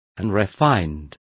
Complete with pronunciation of the translation of unrefined.